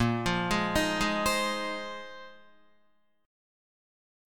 A#9sus4 Chord